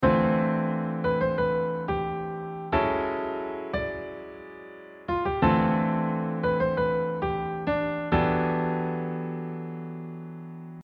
In this case the Dm9 is my reference chord that I used to create contrasts but instead you can create a succession of chords.
harmonic contrasts - real parallel chord motion.mp3